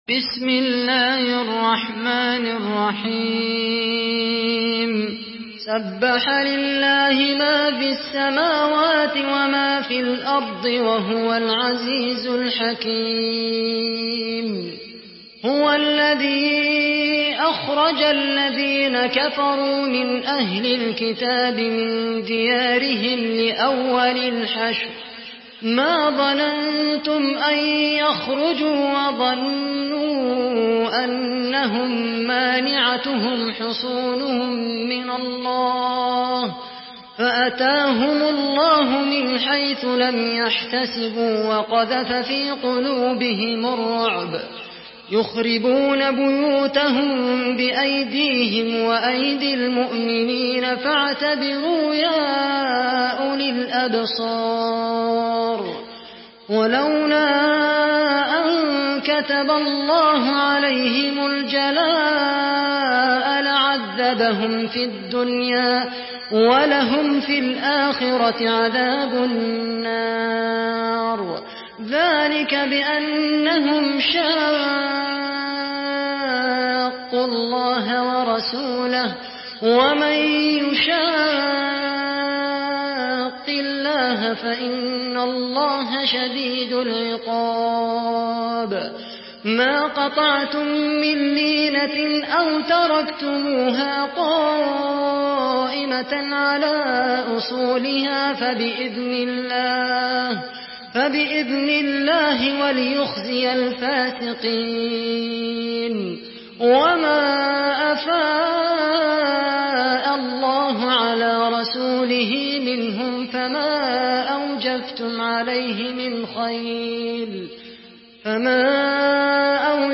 Surah আল-হাশর MP3 by Khaled Al Qahtani in Hafs An Asim narration.
Murattal Hafs An Asim